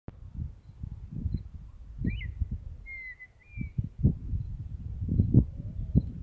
Colorada (Rhynchotus rufescens)
Nombre en inglés: Red-winged Tinamou
Localidad o área protegida: Reserva Natural Urbana La Malvina
Condición: Silvestre
Certeza: Vocalización Grabada